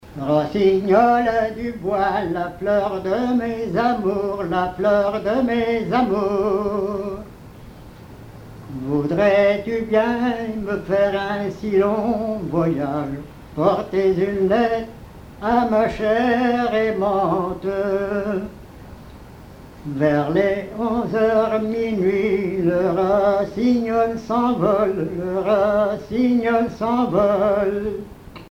Genre strophique
Chansons traditionnelles
Pièce musicale inédite